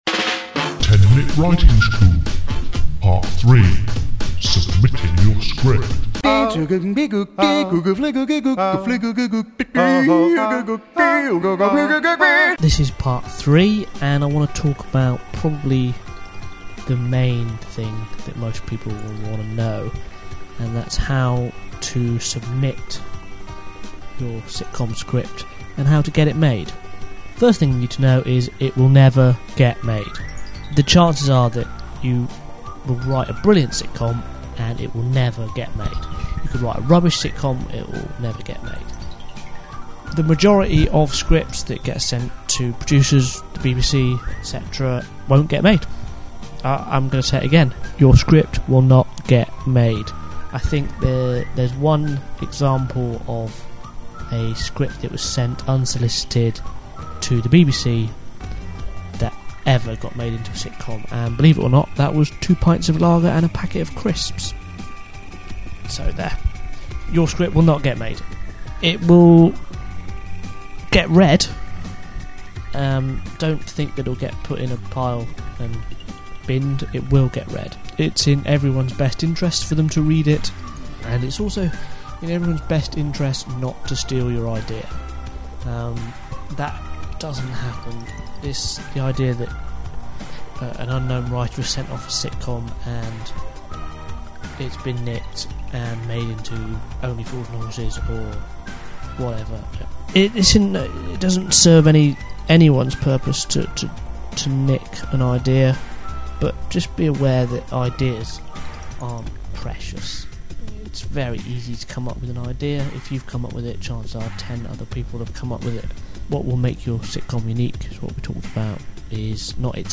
They’re just me talking into a microphone, rambling about my thoughts on certain subjects.